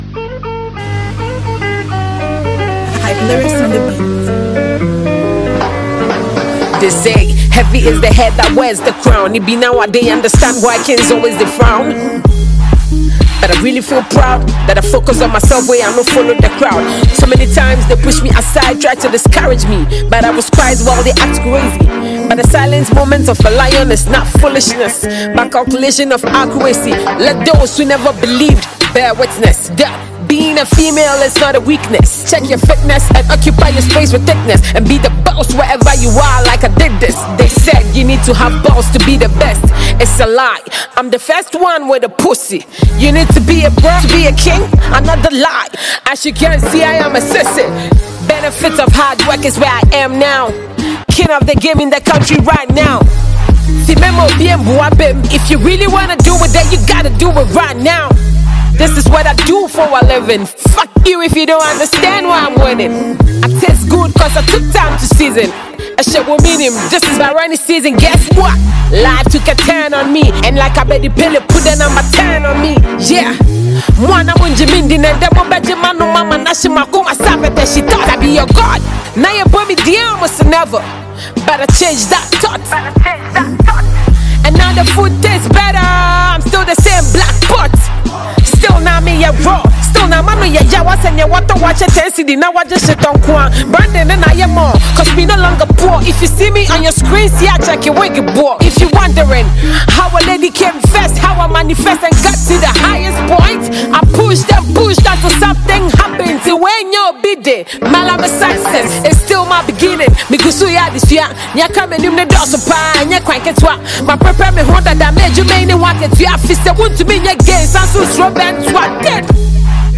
Ghana Music Music
Ghanaian hardcore female Rapper